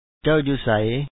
cA$w ju#u sa1y